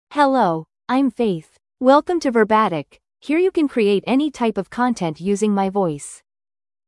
FemaleEnglish (United States)
FaithFemale English AI voice
Faith is a female AI voice for English (United States).
Voice sample
Listen to Faith's female English voice.